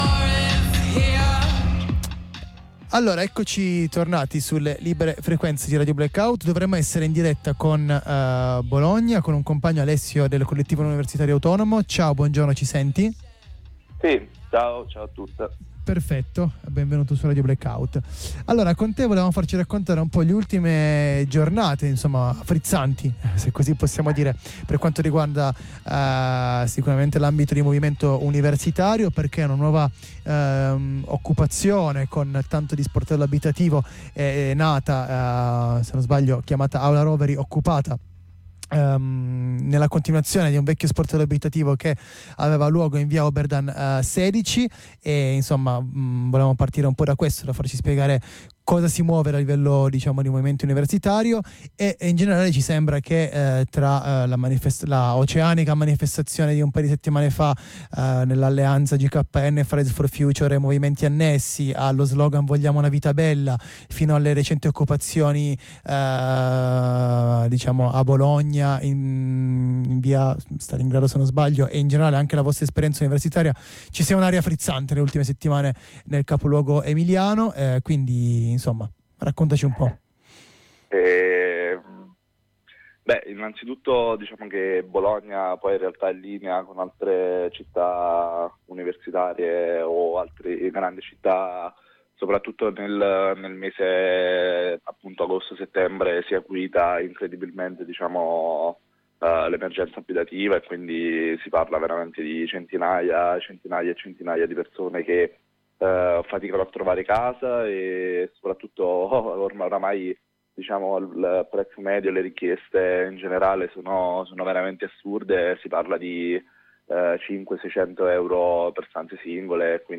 Dopo lo sgombero di Via Oberdan 16, il CUA ha deciso di occupare l’Aula Roveri al piano terra di via Zamboni 38, per renderlo uno sportello sociale e abitativo. Di questo, e di una rinnovata aria frizzante che si respria in città, ne parliamo con un compagno: